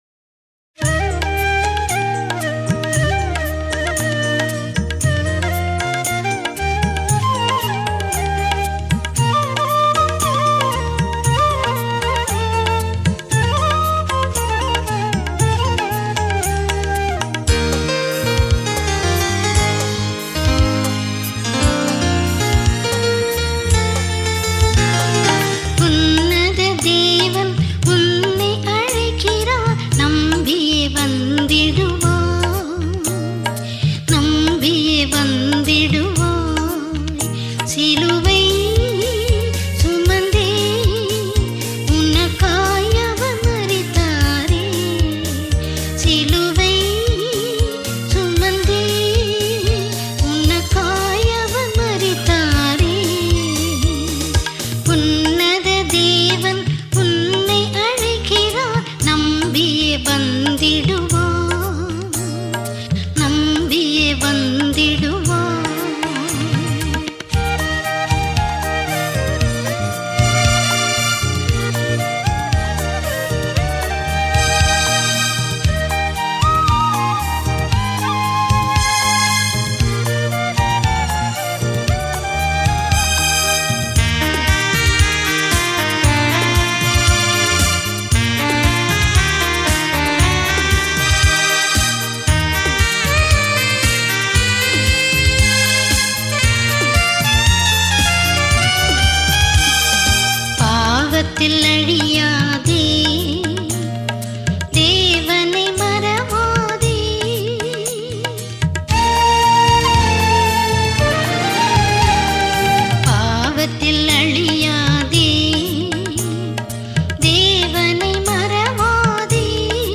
Royalty free Christian music.